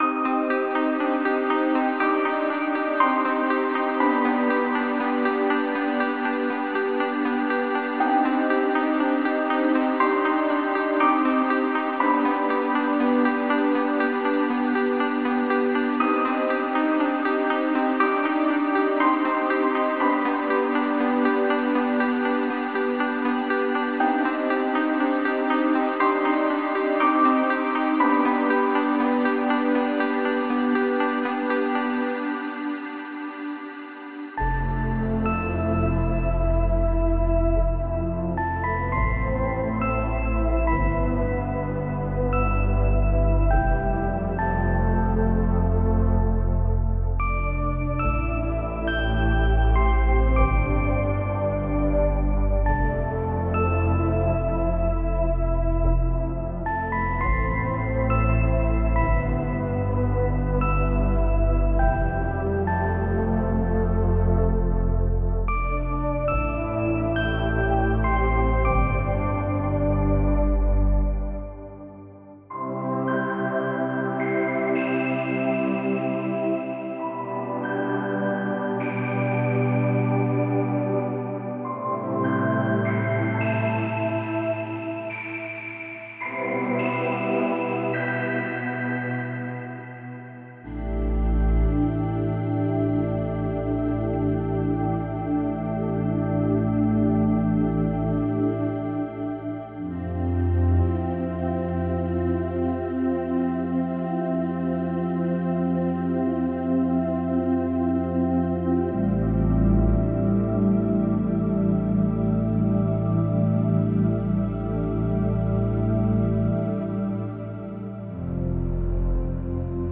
Ambient Cinematic / FX